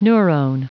Prononciation du mot neurone en anglais (fichier audio)
Prononciation du mot : neurone